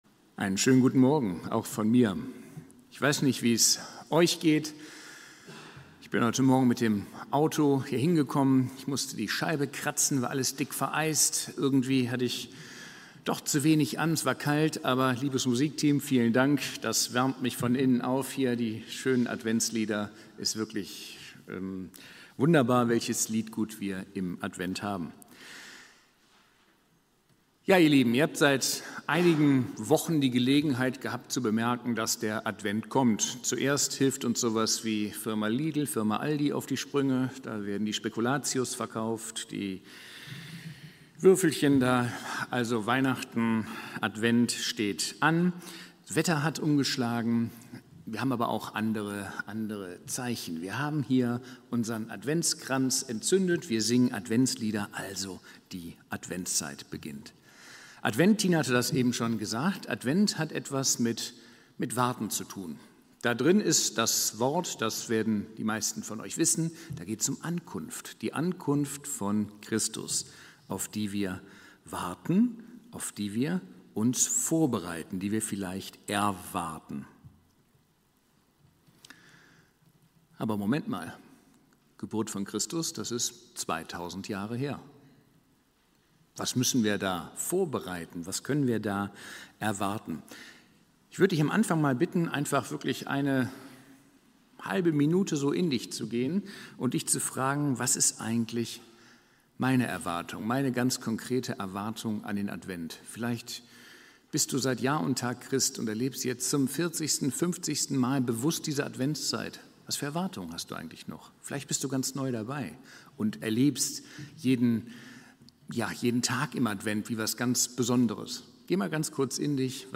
Predigt-am-01.12-online-audio-converter.com_.mp3